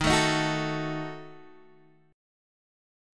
D#dim chord {x 6 7 8 7 5} chord
Dsharp-Diminished-Dsharp-x,6,7,8,7,5.m4a